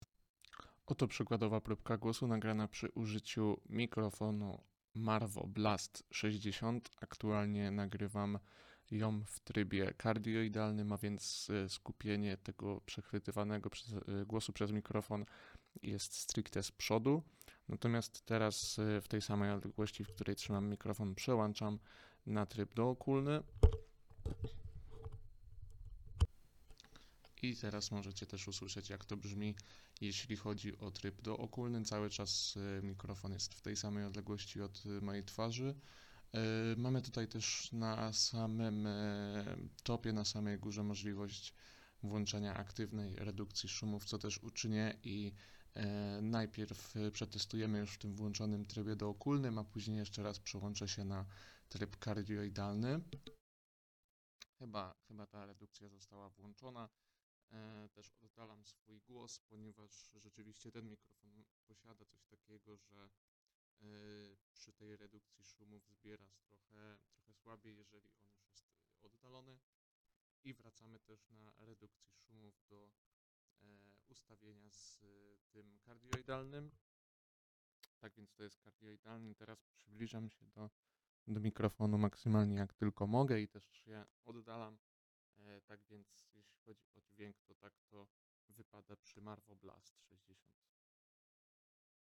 Dźwięk z Marvo Blast 60
Coś, co jako pierwsze rzuca mi się na myśl w kontekście dźwięku z Blasta 60 jest jego czystość.
Audio generowane z tego urządzenia nie równa się temu, które generowane jest przez „majki” od słuchawek.
Marvo oddało też aktywną redukcję szumów, która sprawdza się całkiem nieźle, ale tylko wtedy, gdy produkt jest blisko naszej twarzy. Każde oddalenie się i mówienie w dalszej odległości sprawia, że słychać nas coraz mniej.